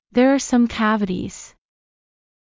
ｾﾞｱ ﾗｰ ｻﾑ ｷｬﾋﾞﾃｨｽﾞ